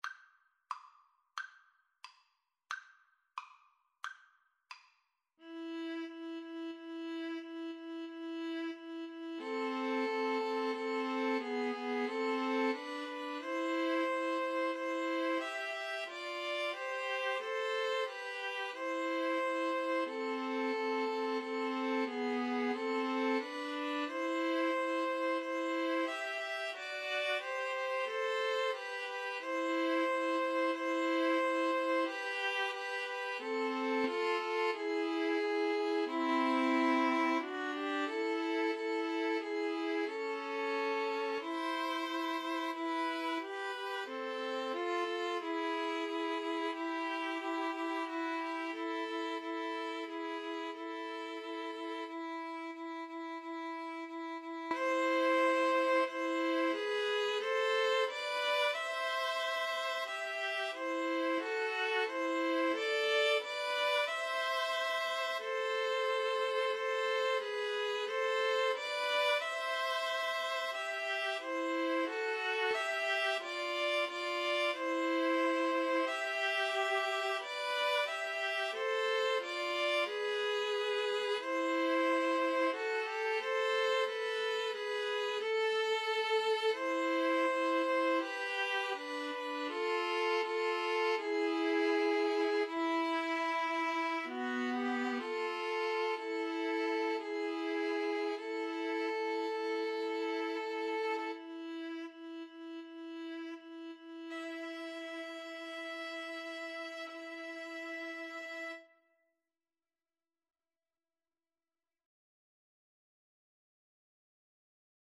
String trio version
=90 Allegretto, ma un poco lento
Classical (View more Classical String trio Music)